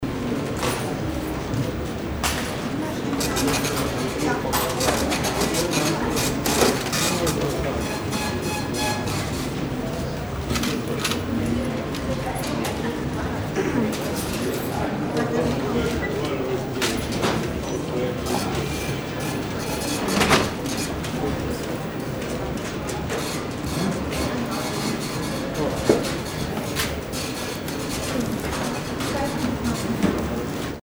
Gemafreie Sounds: Groß- und Einzelhandel
mf_SE-5652-store_cash_2.mp3